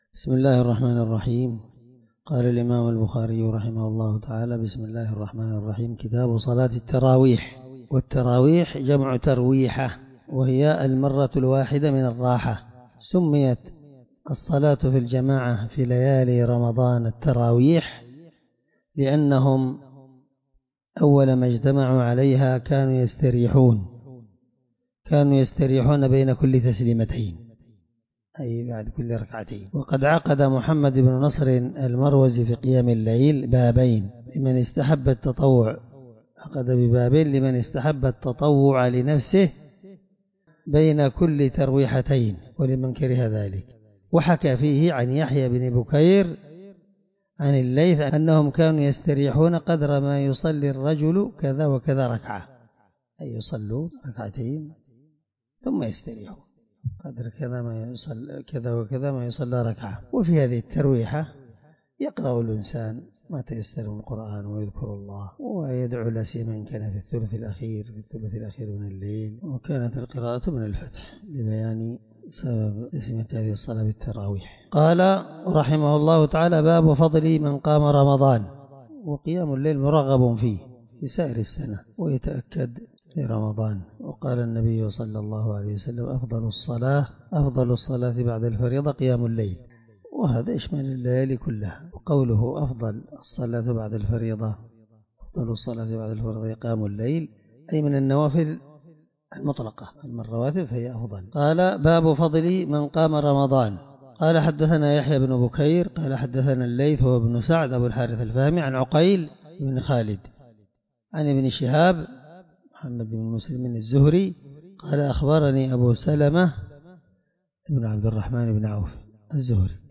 الدرس1من شرح كتاب صلاة التراويح حديث رقم(2008-2010)من صحيح البخاري